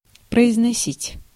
Ääntäminen
IPA: /prəɪzʲnɐˈsʲitʲ/